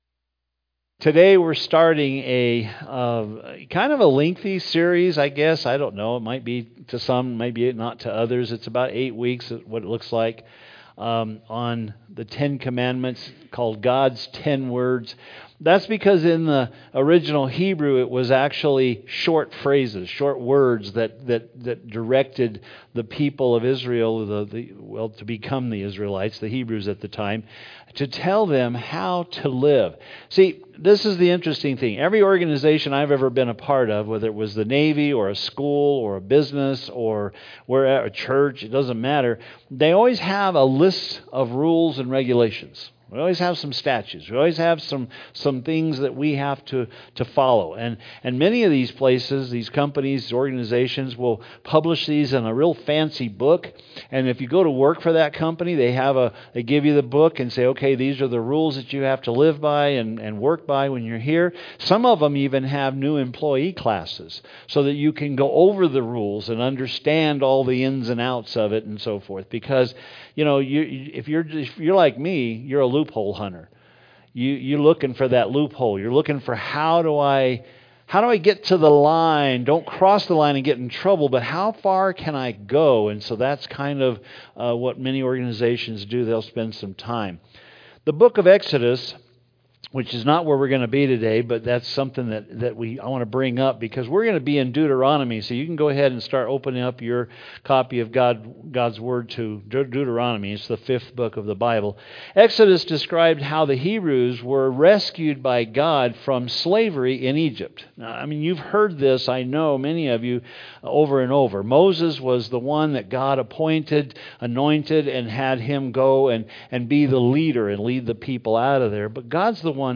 In today's sermon series, we see the people being reminded by Moses of the commands the LORD as a new generation is preparing to enter into the Promise Land. The LORD GOD, has given the commands for all people for all time.